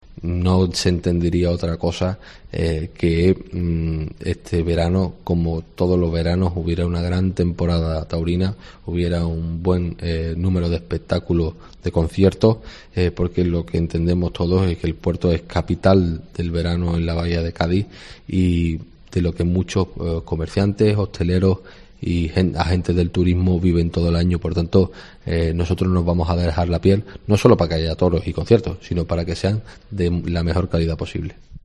Germán Beardo, alcalde de El Puerto, sobre las corridas de toros
Ya lo aseguraba el alcalde Germán Beardo, en los micrófonos de la Cadena Cope, cuando el pasado 19 de junio, en una entrevista que manteníamos con él, adquiría un compromiso.